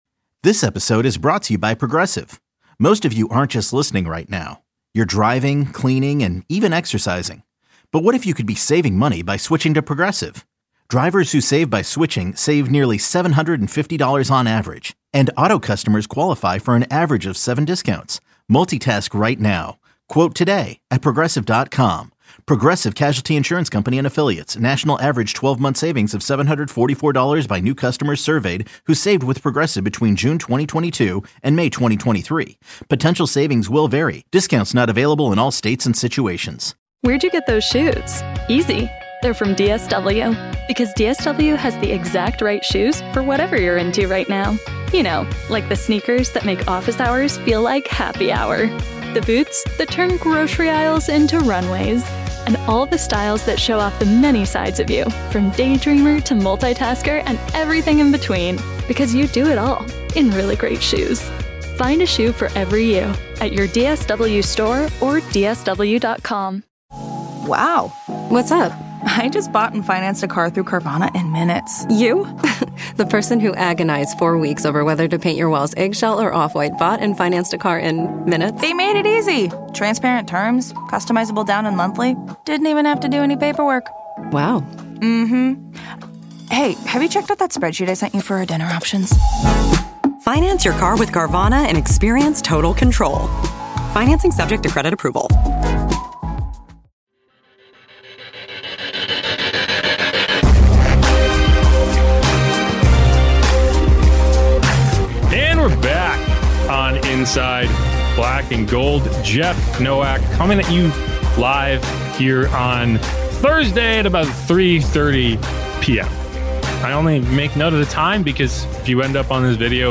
Saints interviews, press conferences and more